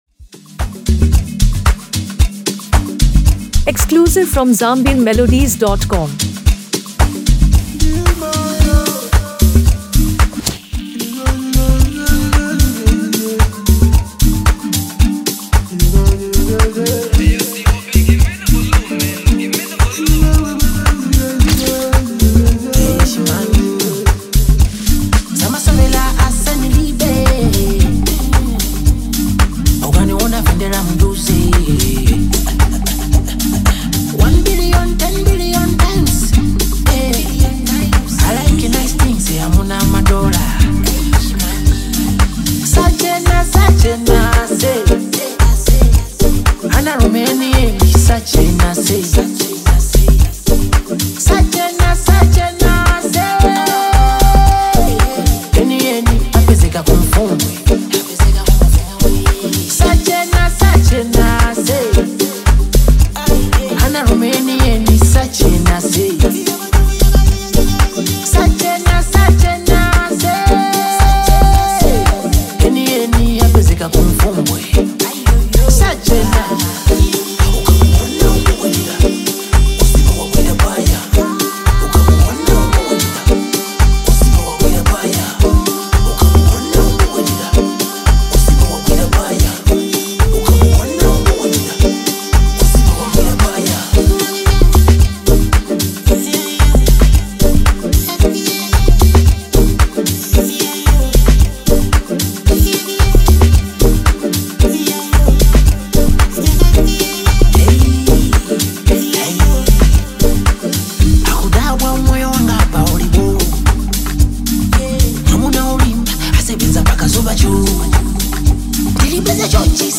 gaining recognition for its catchy hook and replay value.